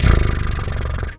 cat_2_purr_01.wav